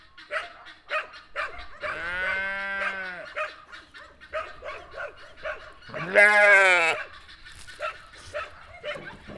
• sheep baaing dog and goose in their background.wav
Recorded with a Tascam DR 40.